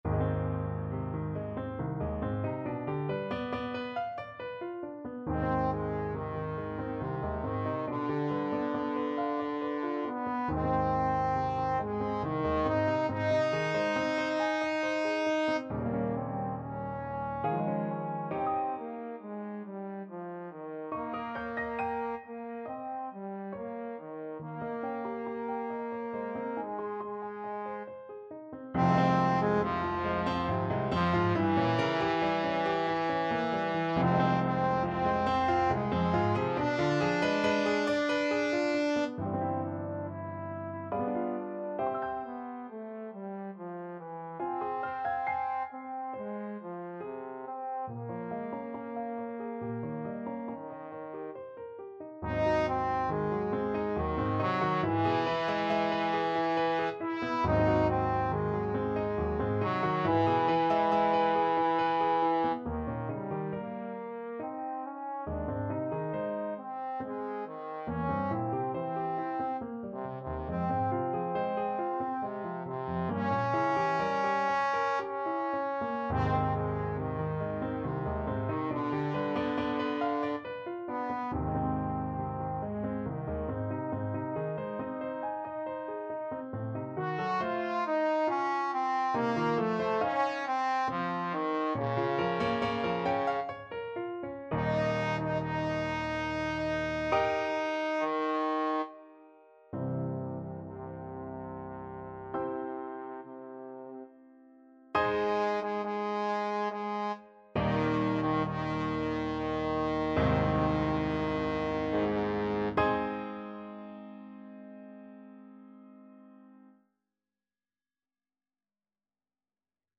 Trombone
Ab major (Sounding Pitch) (View more Ab major Music for Trombone )
3/4 (View more 3/4 Music)
~ = 69 Large, soutenu
voix-supreme_TBNE.mp3